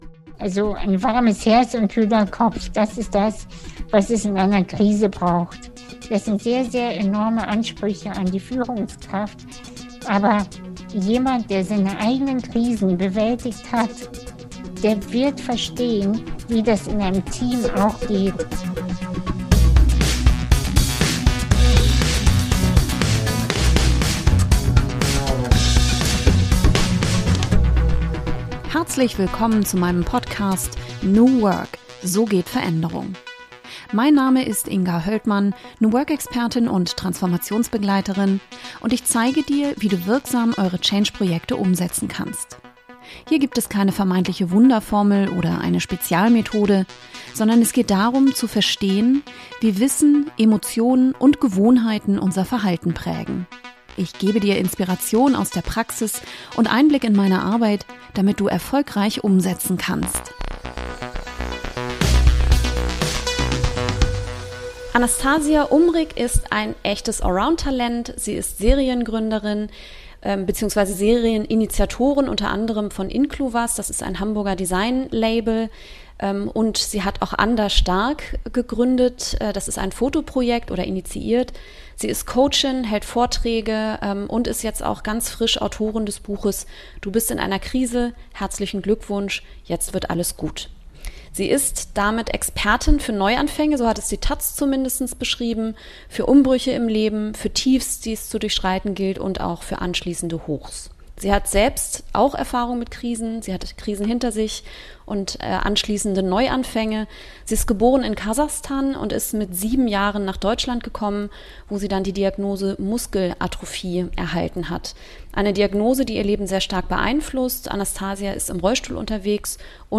Interview | Veränderung als Krise und wie wir mit ihr umgehen können ~ New Work – so geht Veränderung Podcast